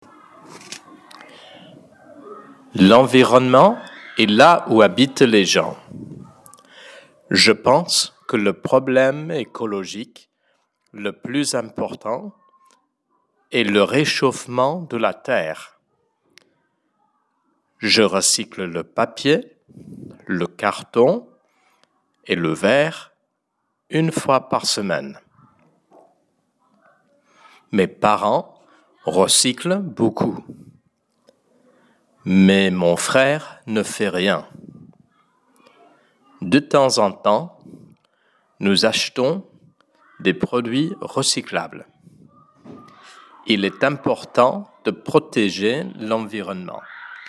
Lecture à haute voix : 3.3 L’environnement et là où habitent les gens (F) – Lingo Bennies!